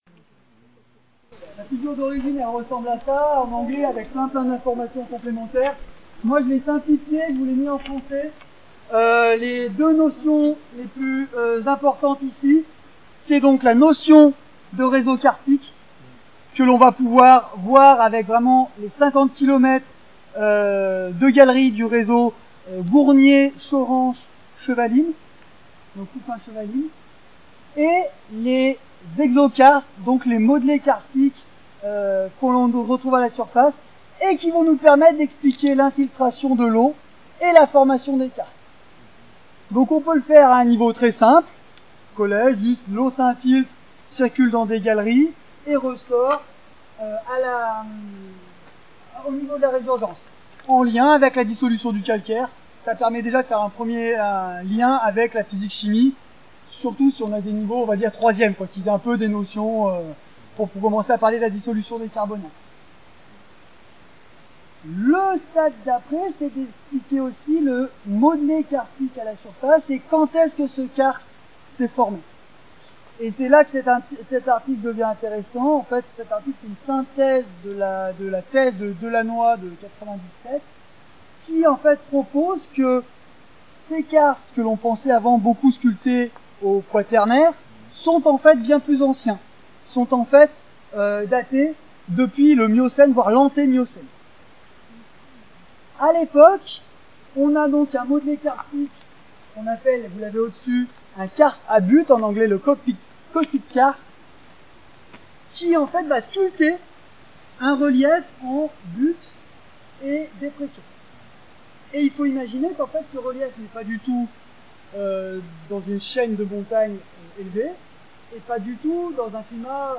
extrait audio (excusez la qualité !)